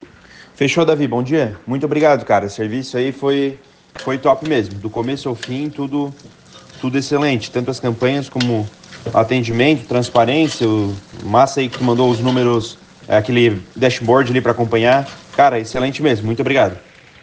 Depoimentos